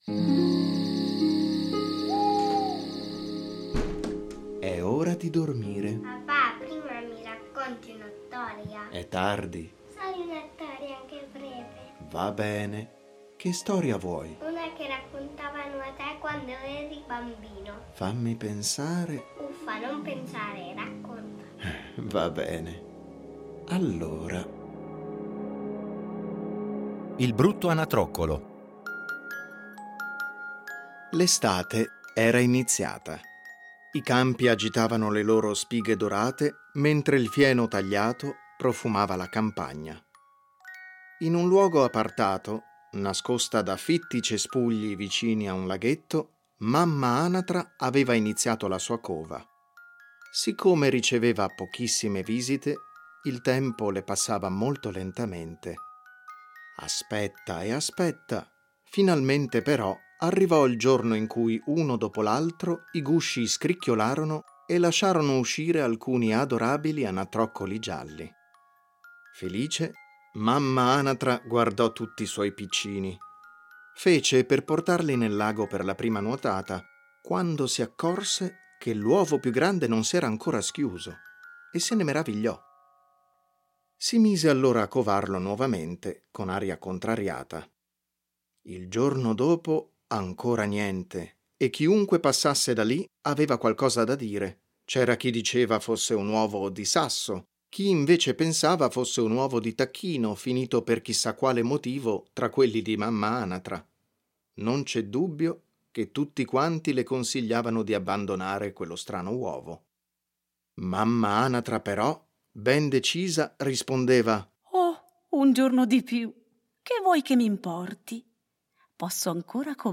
A partire dai testi originali un adattamento radiofonico per fare vivere ai bambini storie conosciute, ma un po’ dimenticate.